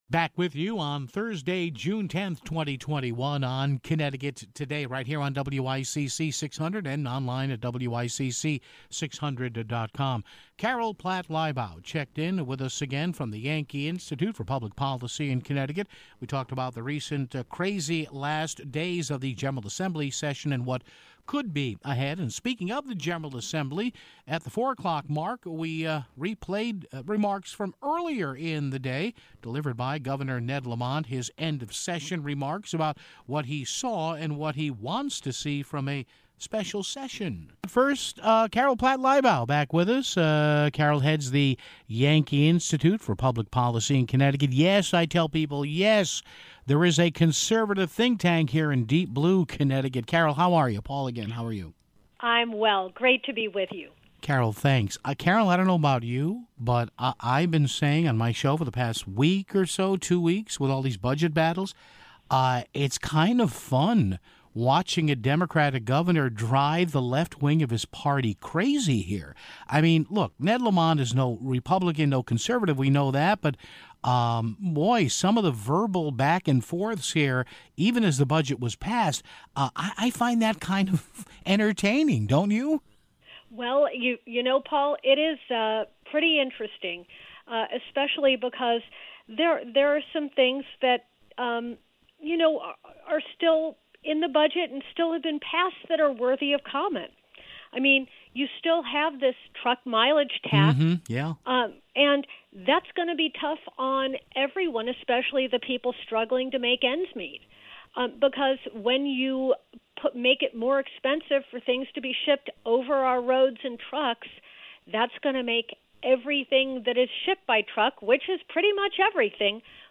We also carried portions of Governor Ned Lamont’s most recent meeting with the press (11:49).